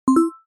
dingDing
bell chime ring sound effect free sound royalty free Sound Effects